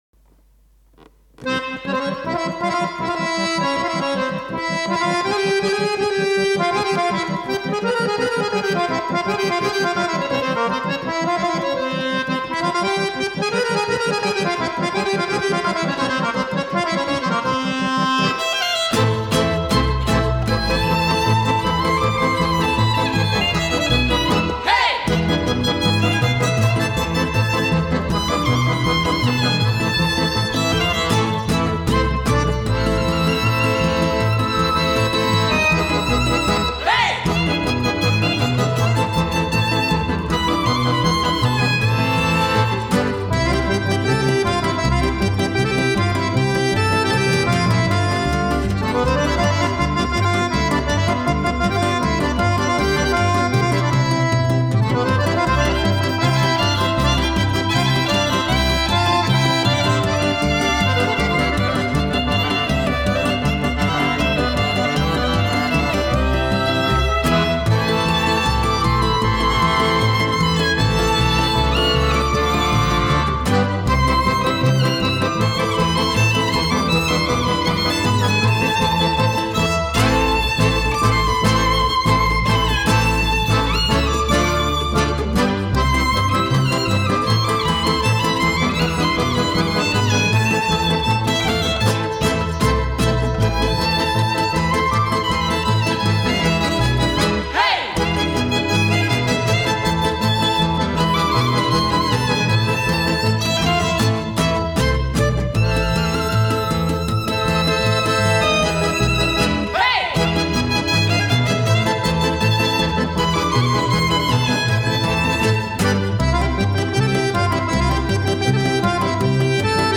Akustična etno skupina